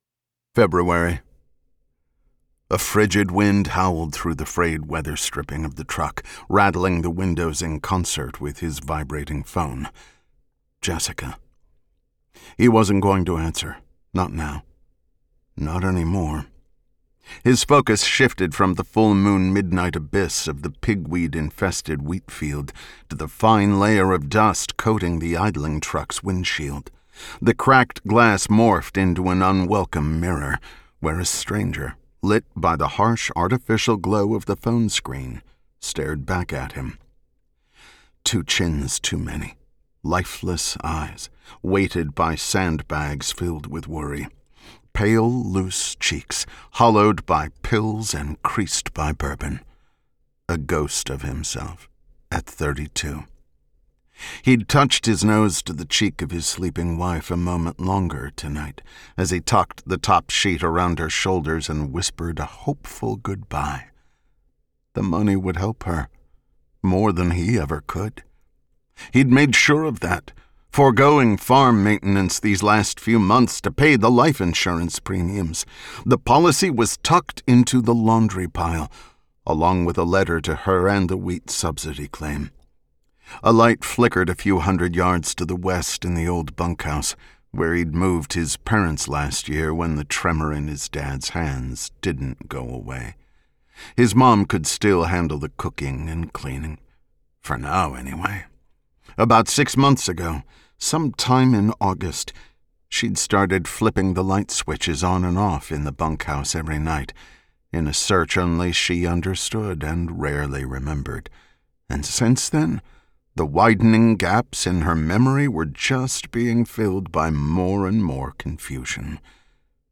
Listen to legendary narrator Scott Brick (storytelling voice for Tom Clancy, Robert Ludlum, John Grisham, and many more) deliver the opening chapter of Ordinary Soil, and let us know if you’d like to be notified when we release the audiobook!